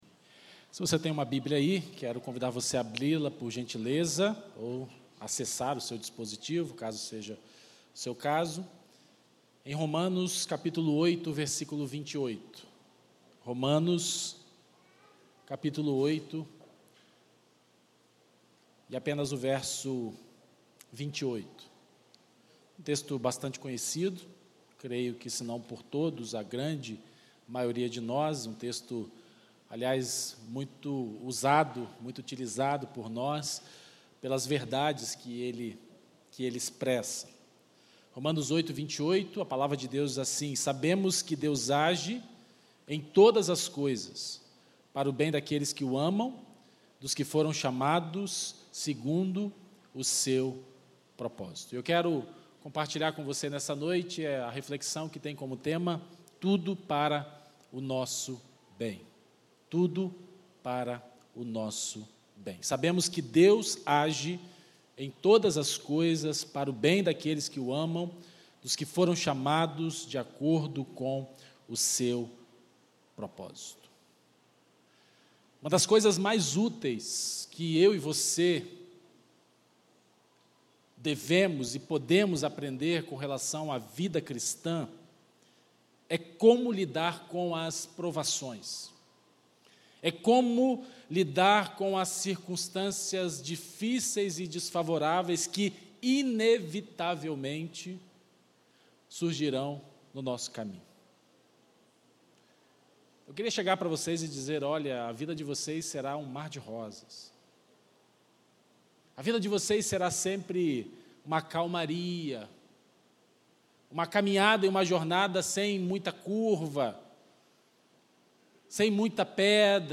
na Primeira Igreja Batista do IPS